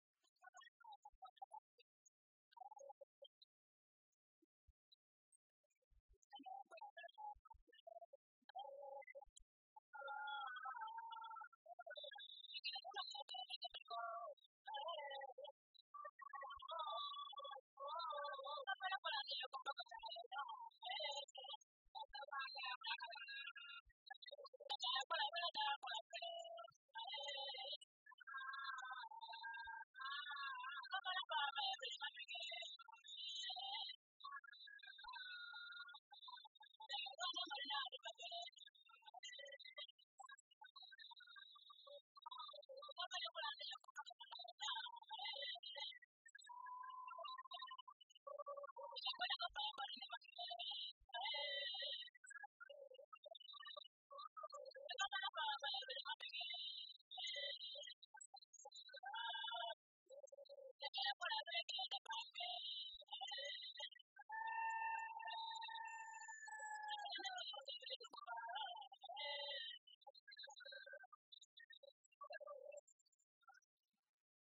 Zande women
Folk songs, Zande
Field recordings
sound recording-musical
The song is sung around the body of the dead person. There is little to indicate from the style and manner of singing that they indicate sorrow or mourning. To an outsider, Zande mourning songs sound as unconcerned with death as similar songs in other African tribes. Mourning song.